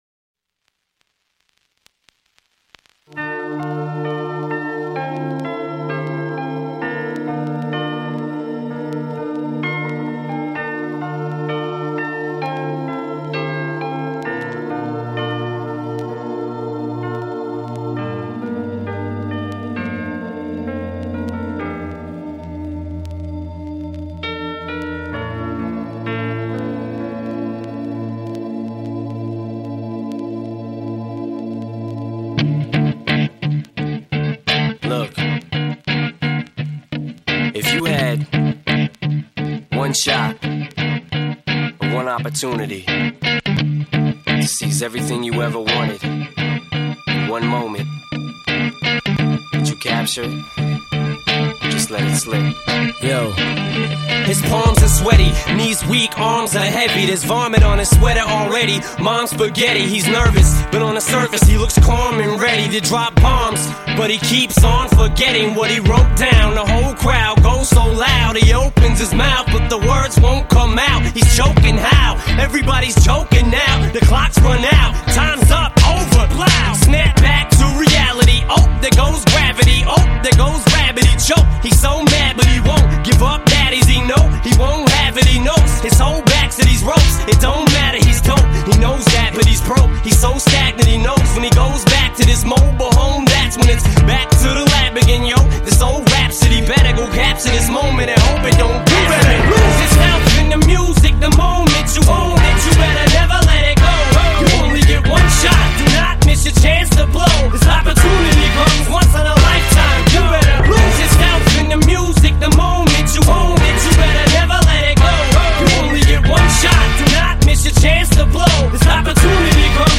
Жанр: Hip-Hop